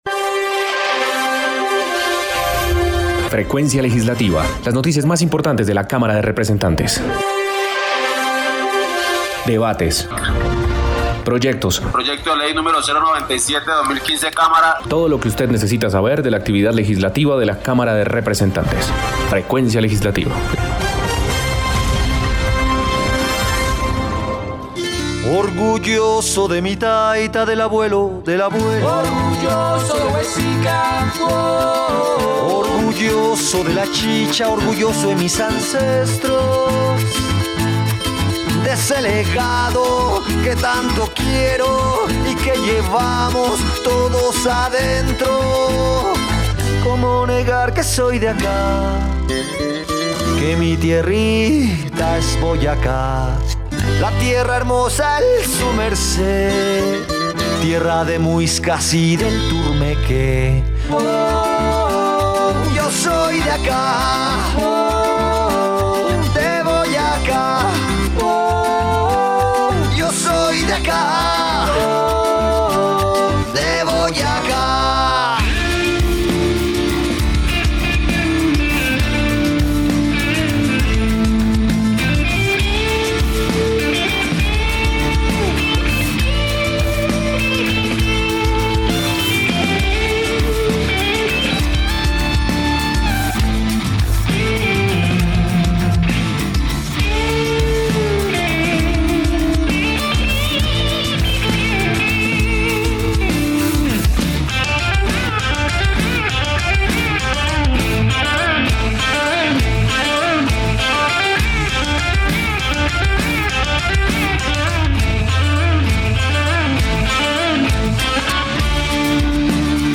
Programa Radial Frecuencia Legislativa.